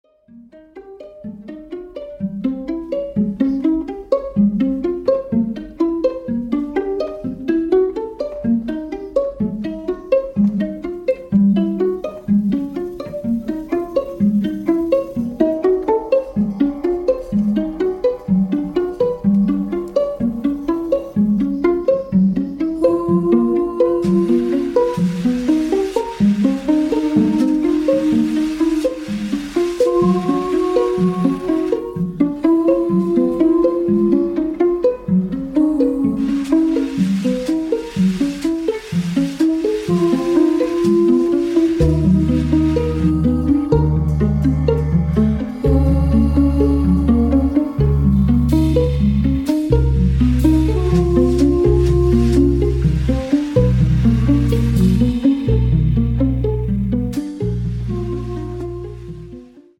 Haunting atmospheric jazz